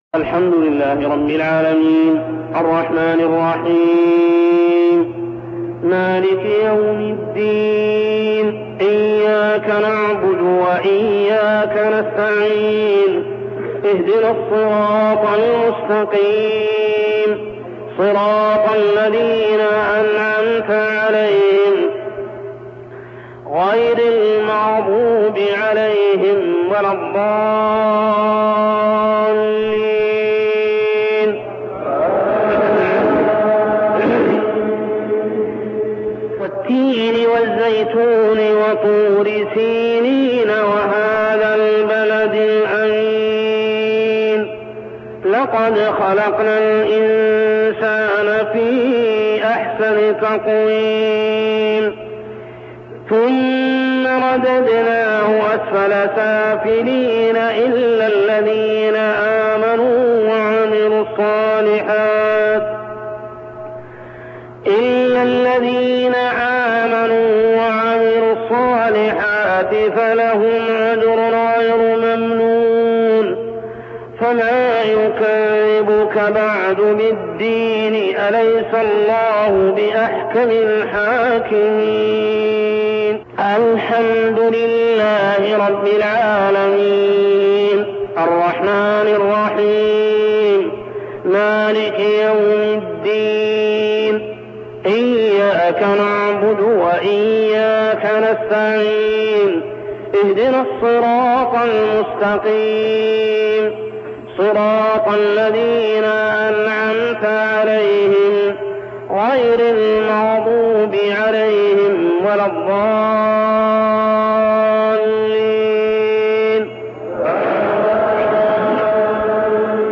تلاوة من صلاة المغرب لسورتي التين و العصر كاملة عام 1402هـ | Isha prayer Surah Al-Tin and al-`Asr > 1402 🕋 > الفروض - تلاوات الحرمين